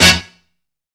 BASSIN HIT.wav